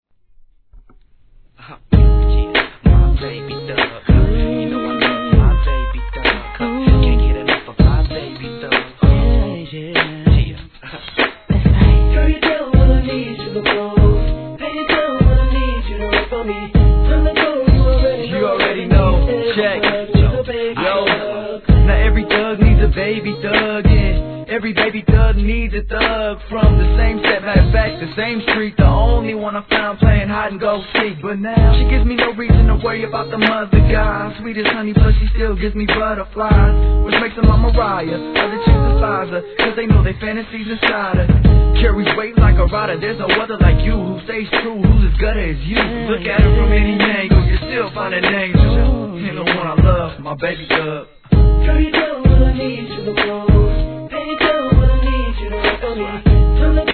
HIP HOP/R&B
歌物好きにもお勧めな仕上がりです♪